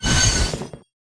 Index of /App/sound/monster/ice_snow_monster
dead_1.wav